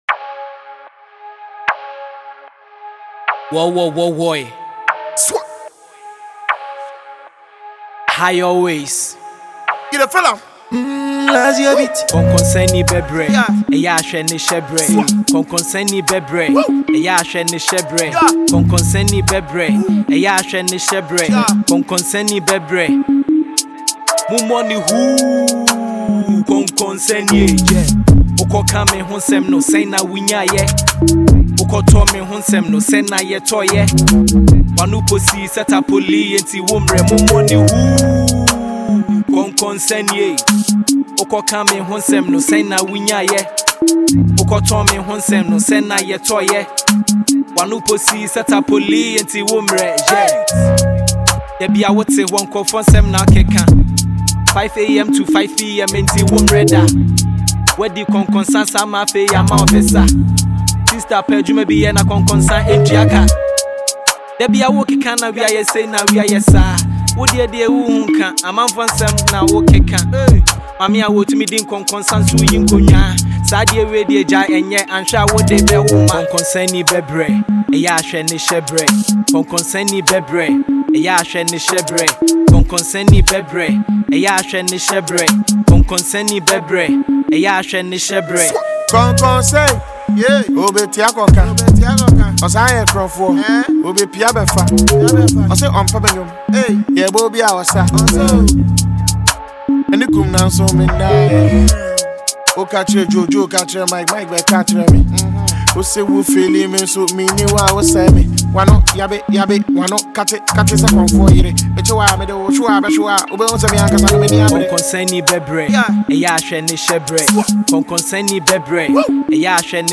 Ghana MusicMusic
Ghanaian budding rapper
award-winning rapper